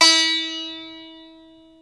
ETH XSITAR0F.wav